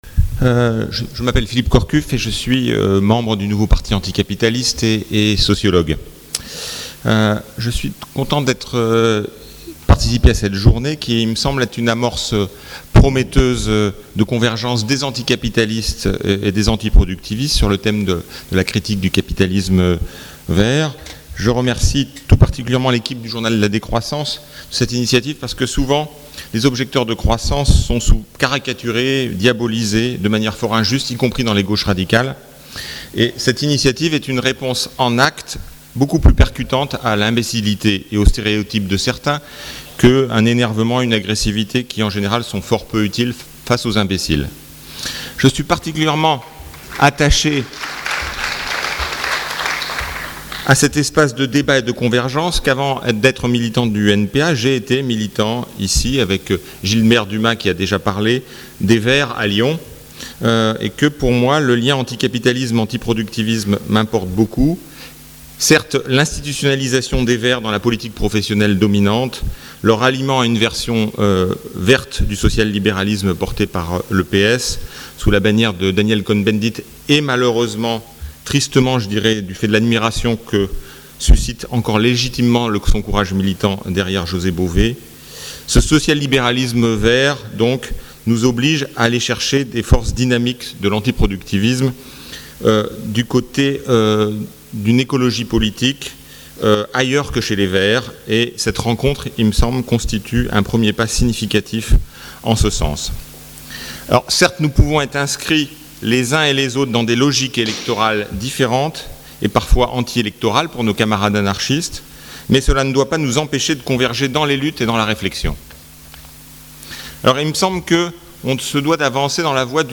Une intervention en tant que membre du NPA lors du Contre-Grenelle 2 « Non au capitalisme vert », organisé par le journal La Décroissance le samedi 2